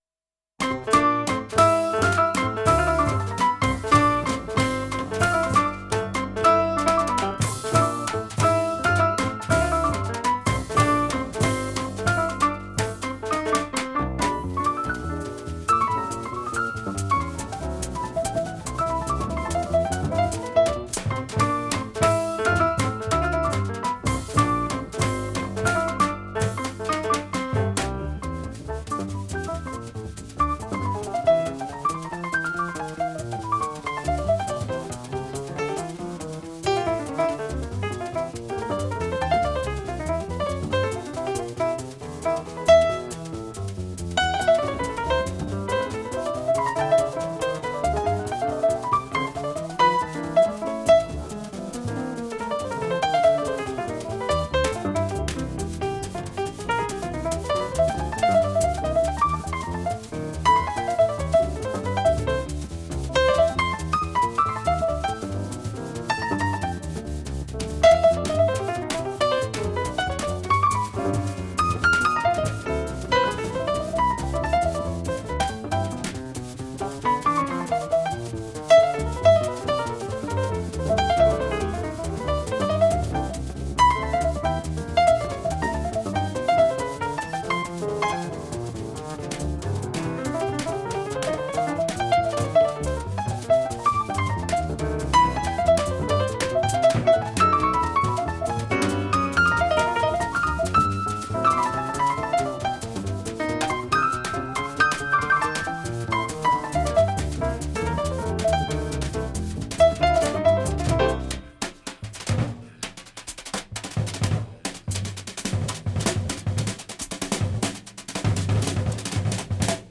JAZZ PIANO SOLOS - AUDIO FOR SOLO TRANSCRIPTIONS